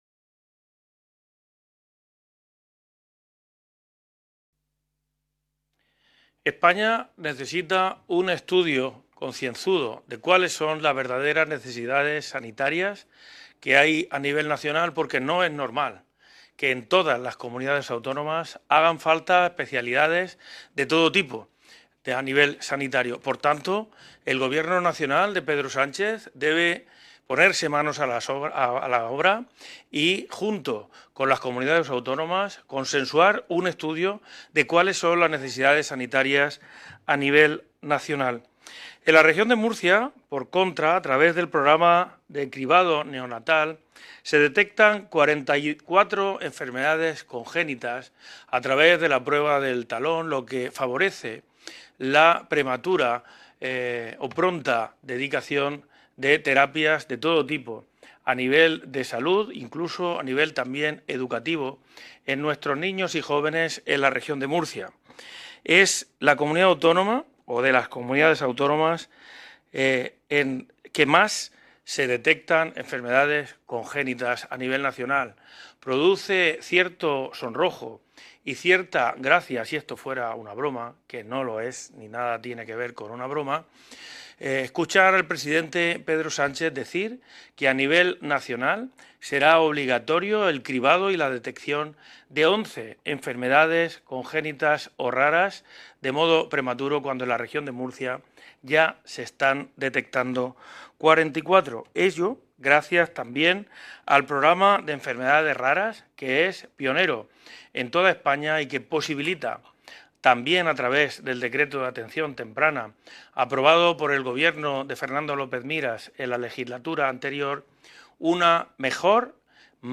Ruedas de prensa posteriores a la Comisión Especial de Estudio sobre Infancia y Adolescencia
• Juan José Pedreño Planes, consejero de Salud
• Grupo Parlamentario Socialista
• Grupo Parlamentario Vox
• Grupo Parlamentario Popular